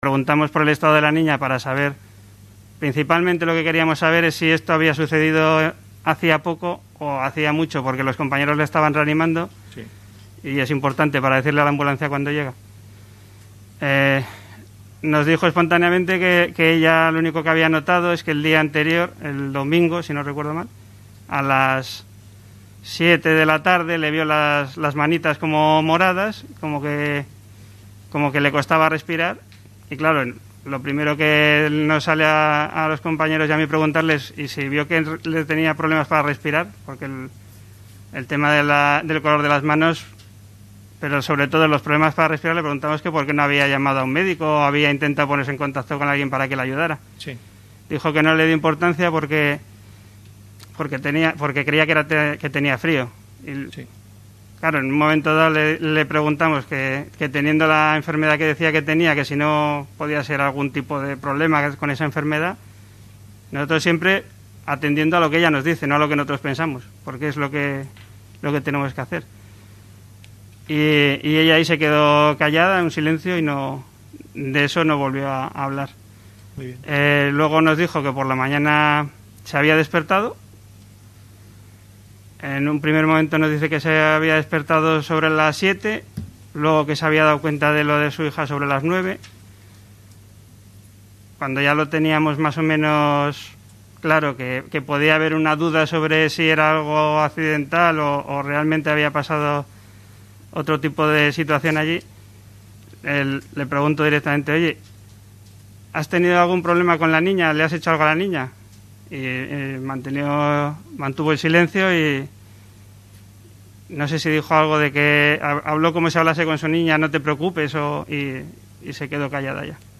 JUICIO-NIÑA_.mp3